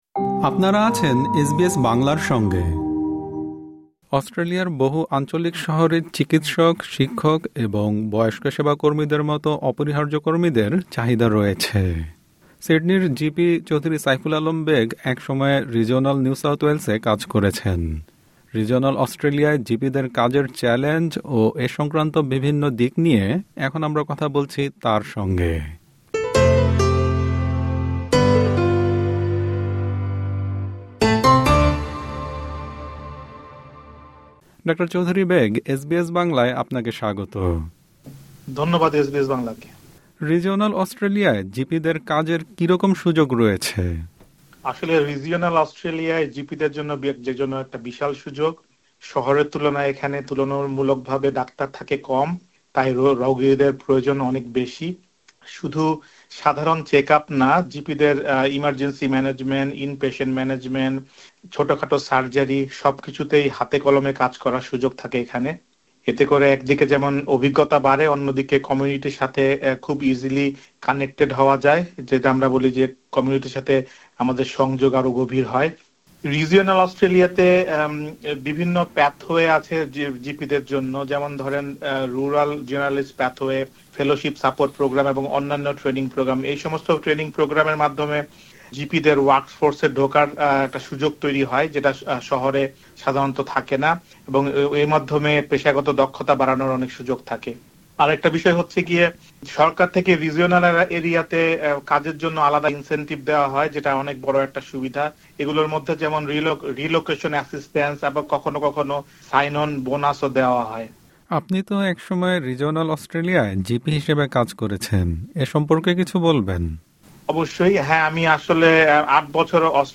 এসবিএস বাংলার সঙ্গে এ নিয়ে কথা বলেছেন তিনি।
সাক্ষাৎকারটি